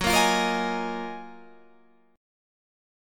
GbM13 chord